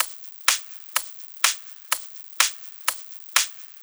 Add Some Kick.wav